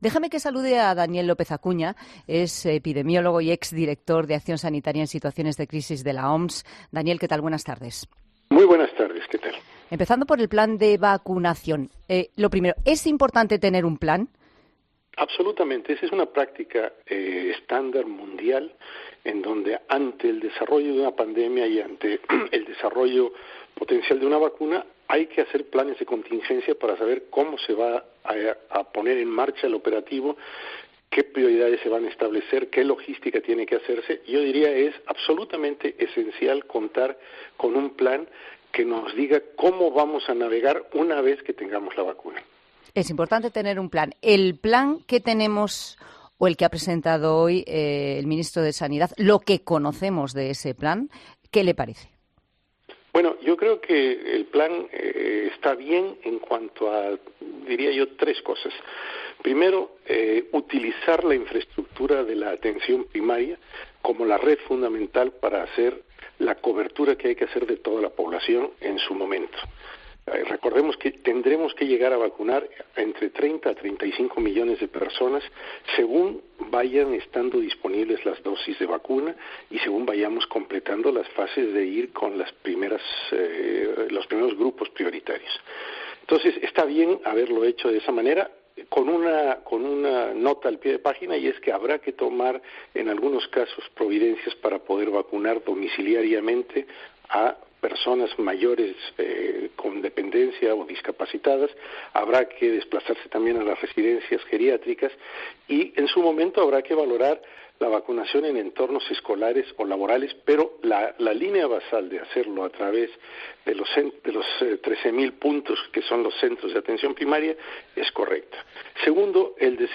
ha pasado por los micrófonos de 'La Tarde'